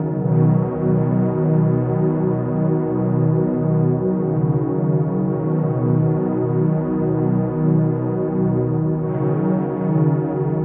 Index of /DESN275/loops/Loop Set - Spring - New Age Ambient Loops/Loops
MindMap_90_B_Pad.wav